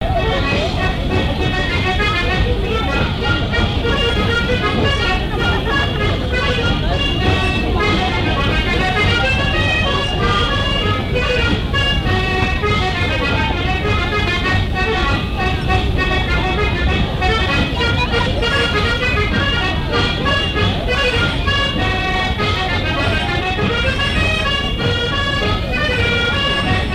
Mémoires et Patrimoines vivants - RaddO est une base de données d'archives iconographiques et sonores.
danse : scottich sept pas
lors d'une kermesse
Pièce musicale inédite